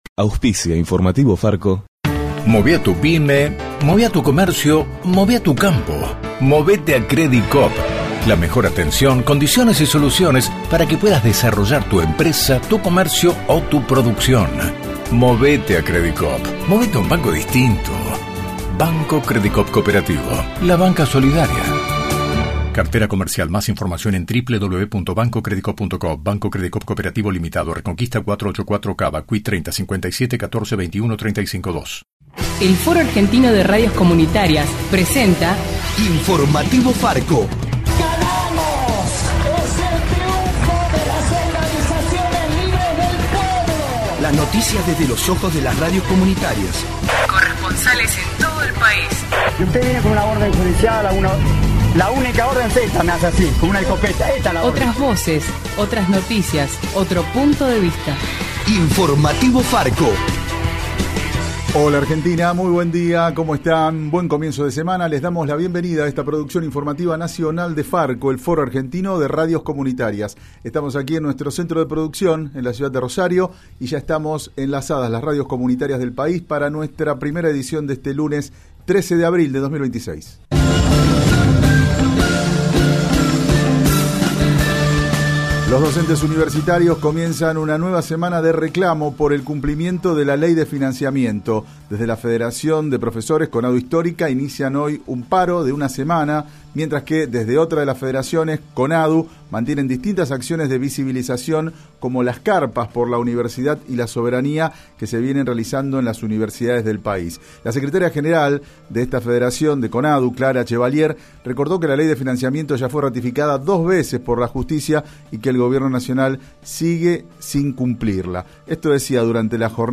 De la mano de FARCO (Foro Argentino de Radios Comunitarias) en Radio Atilra te acercamos el informativo más federal del país.